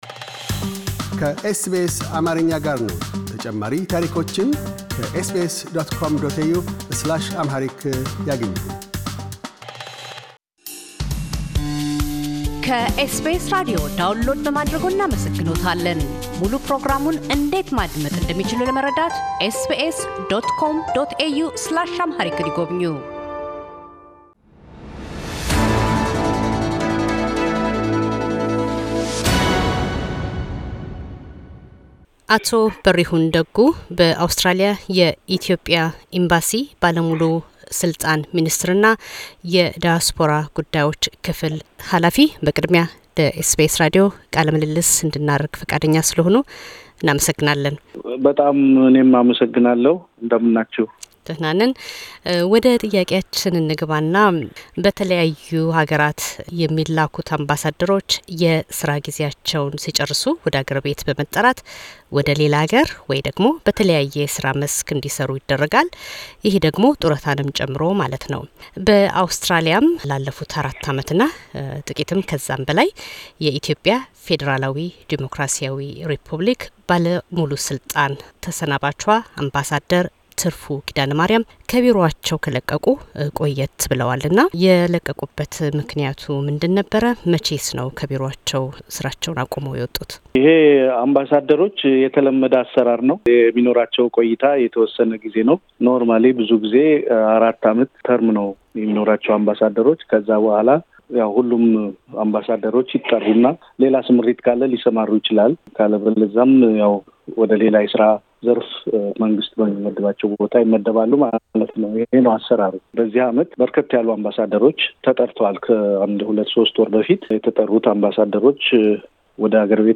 አቶ በሪሁን ደጉ በአውስትራሊያ የኢትዮጵያ ኢምባሲ ባለሙሉ ስልጣን ሚኒስትር እና የዳያስፖራ ጉዳዮች ክፍል ሃላፊ በቅርቡ ከስራቸው በእረፍት ላይ ባሉት በተሰናባቿ ክብርት አምባሳደር ትርፉ ኪዳነማርያም ዙሪያ ላደረግንላቸው ጥያቄዎች ምላሻቸውን ሰጥተዋል።